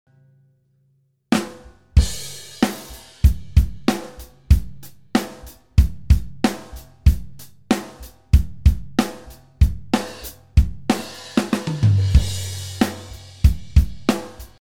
Habs noch mal probiert und der Kompression fast zur Gänze abgeschworen. Einzig der Comp in der Summe ist geblieben, der war meines Erachtens nach unerlässlich. Die EQs sind entschärft, und ich hab darauf geachtet, dass zumindest Sn und Bd in OHs und direkt in Phase sind.